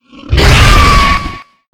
flesh_aggressive_2.ogg